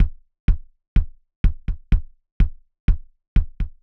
IBI Beat - Kicks.wav